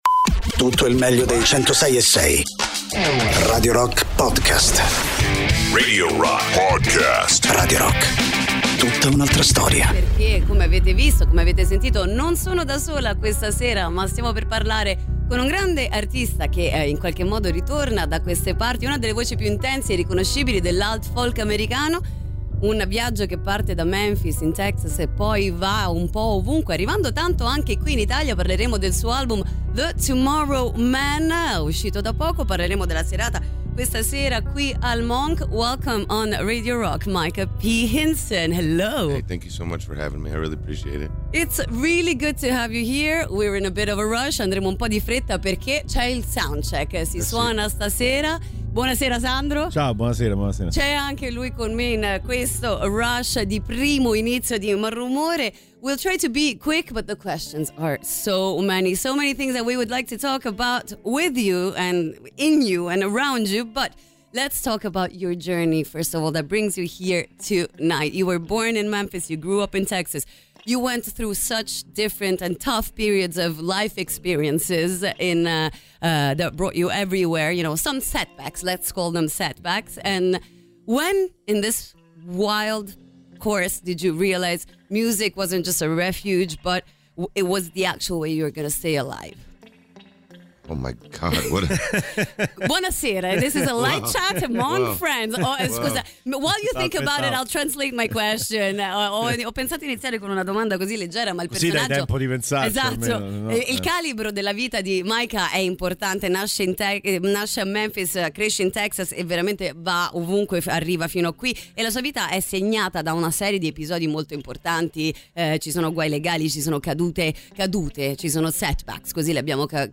Intervista: Micah P. Hinson (19-11-25)